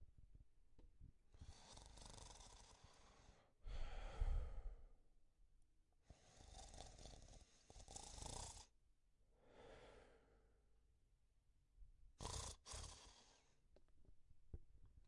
鼾声
描述：记录打鼾的人。
Tag: 随机 睡眠 OWI 恼人的 打鼾